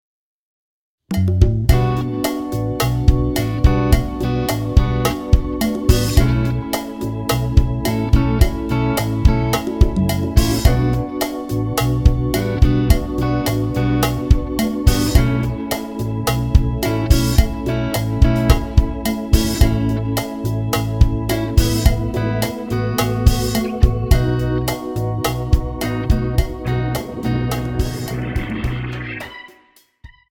Tonart:Bm ohne Chor